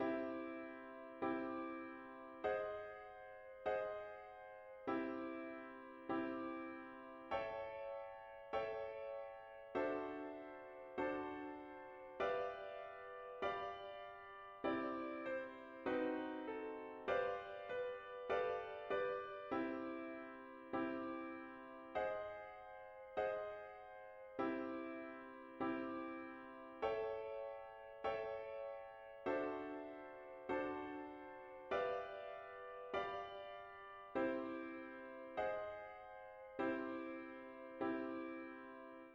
After listening to the chord progression, you should be able to recognize the motivic example as it is now played along with the chord progression.
Sequence- 2 Diatonic Chords with melody
Sequence-2-Diatonic-Chords-with-melody.mp3